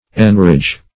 \En*ridge"\